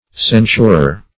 Censurer \Cen"sur*er\, n. One who censures.